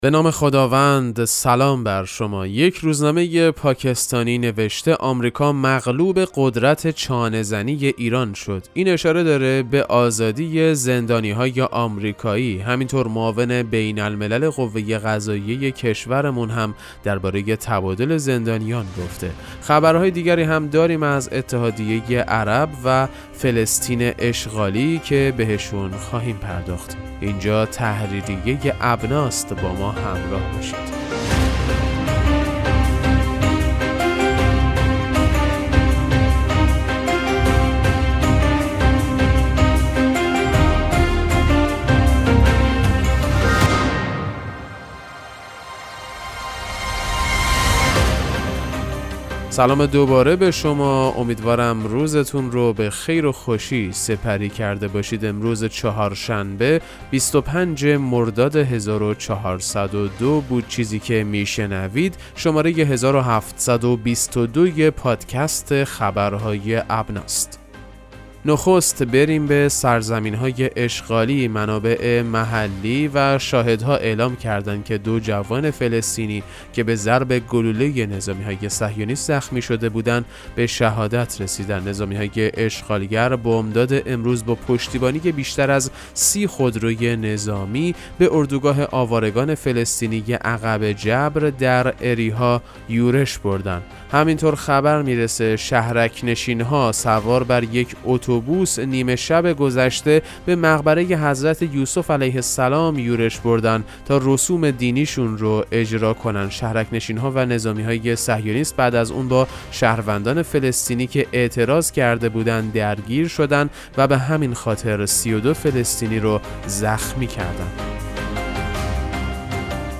پادکست مهم‌ترین اخبار ابنا فارسی ــ 25 مرداد 1402